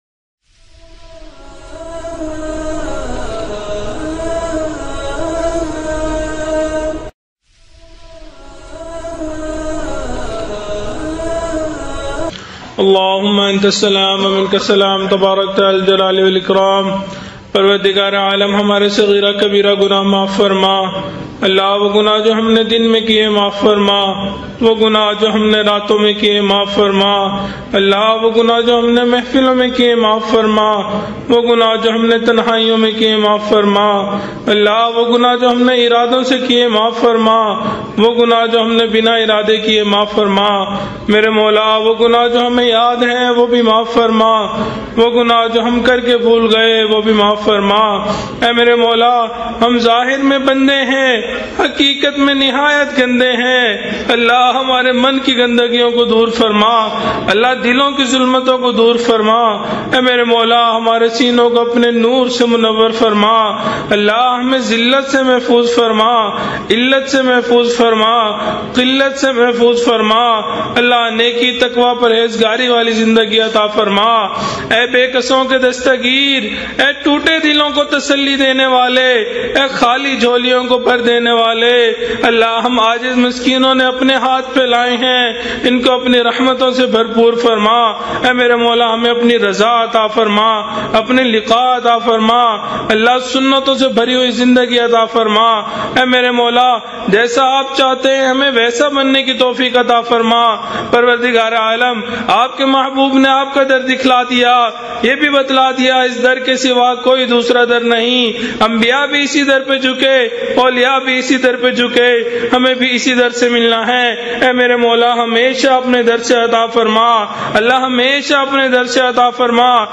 Very Emotional Dua That Will Make You Cry Download MP3
VERY-EMOTIONAL-DUA.mp3